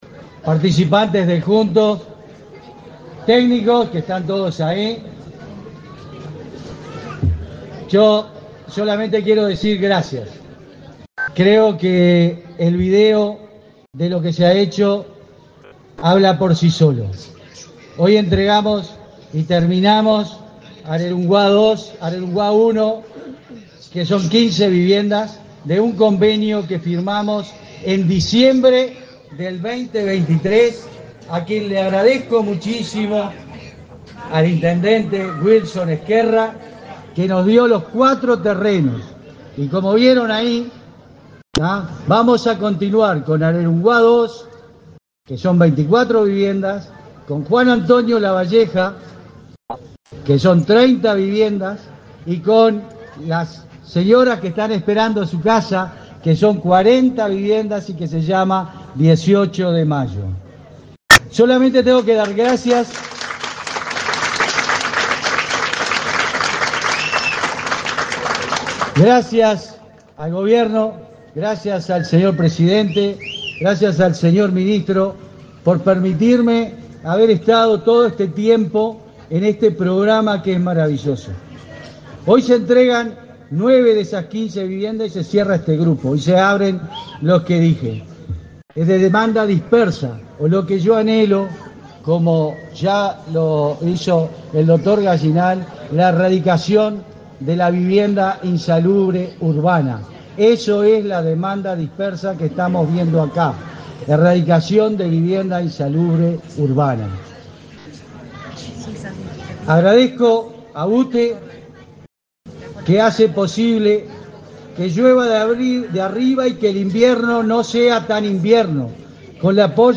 Acto de inauguración de viviendas en Tacuarembó
Con la presencia del presidente de la República, Luis Lacalle Pou, fueron inauguradas, este 2 de setiembre, 9 viviendas del grupo Arerungua en la ciudad de Tacuarembo. En el acto disertaron: el coordinador general de Juntos, Rody Macias; el intendente de Tacuarembó, Wilson Ezquerra, y el ministro de Vivienda y Ordenamiento Territorial, Raúl Lozano.